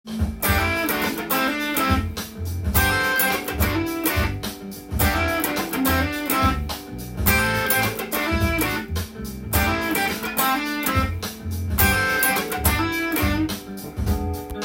ファンキーなギターパート例
③は、ペンタトニックスケールを２音同時に使い
和音にしながらカッティングをするパターンです。
cutting.riff3_.m4a